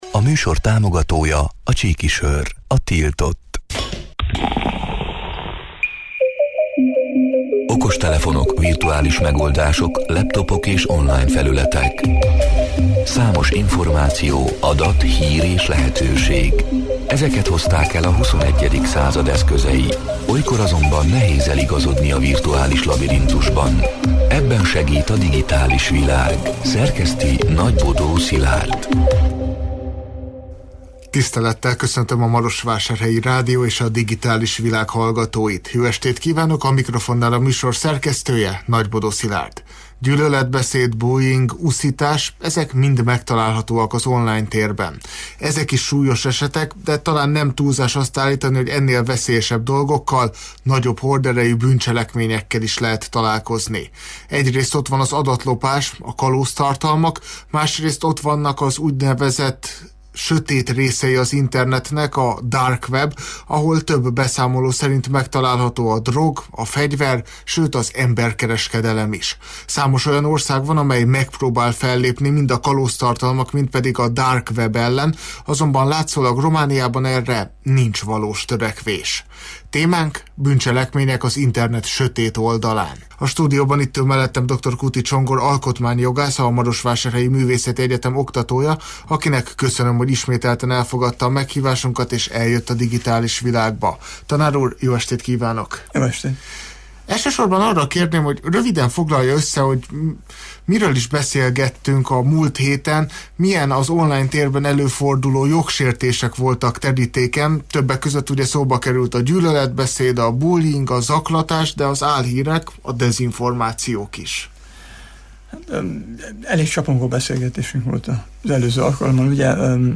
A Marosvásárhelyi Rádió Digitális Világ (elhangzott: 2025. április 15-én, kedden este nyolc órától élőben) c. műsorának hanganyaga: